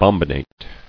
[bom·bi·nate]